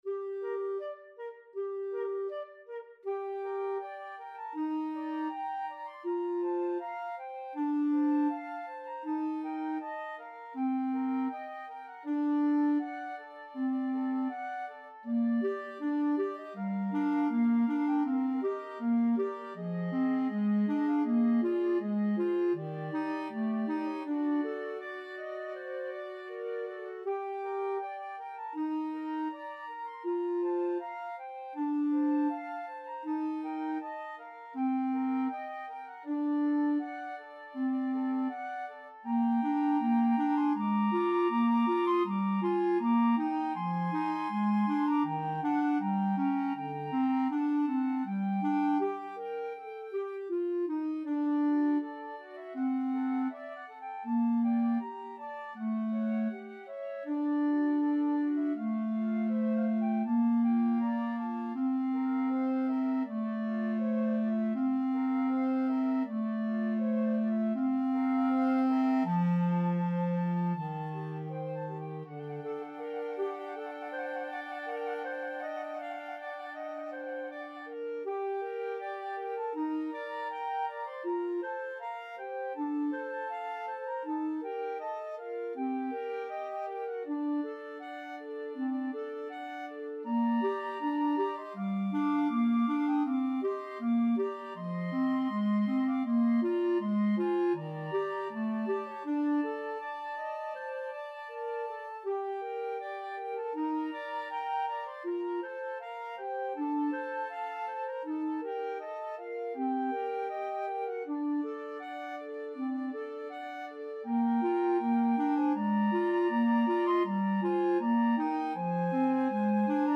Flute 1Flute 2Clarinet
4/4 (View more 4/4 Music)
Andante
Classical (View more Classical 2-Flutes-Clarinet Music)